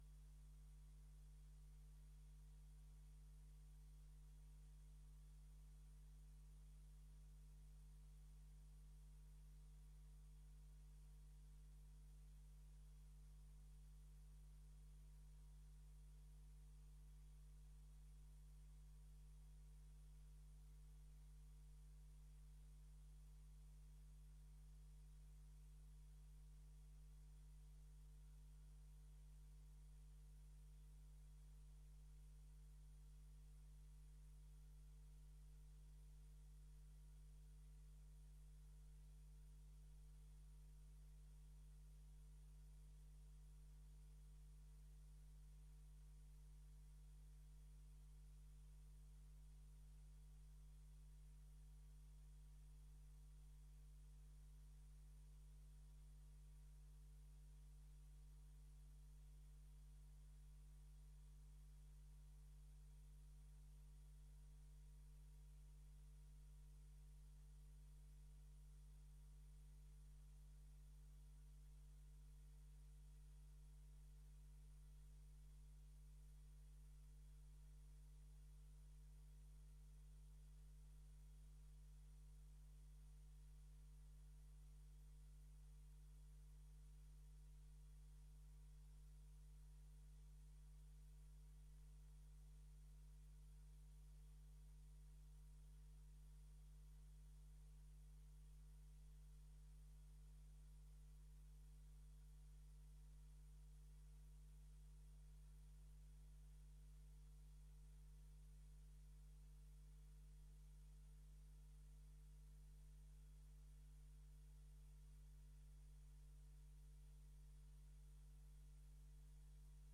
Locatie: Raadzaal Voorzitter: H.A.J. Kleine Koerkamp